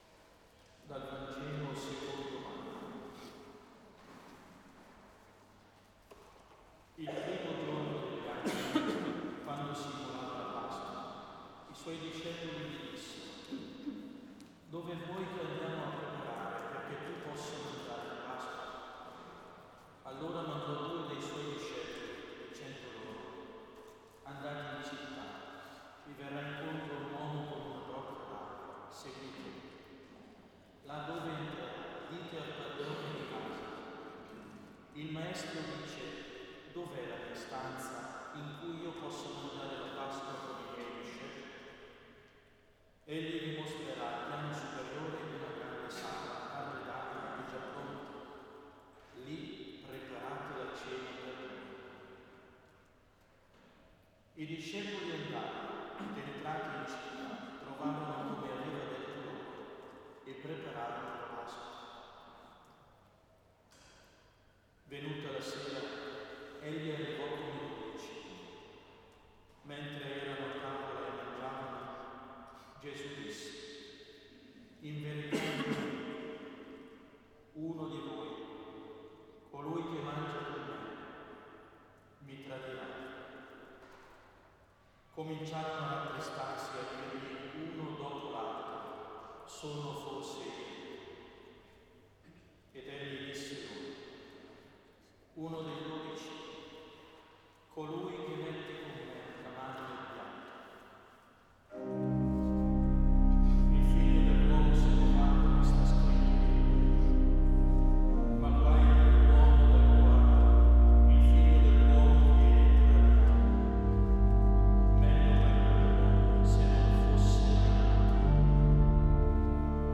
Elevazione Musicale Santa Pasqua 2025.
Basilica di S.Alessandro in Colonna, Bergamo
solo, 4 voci miste e organo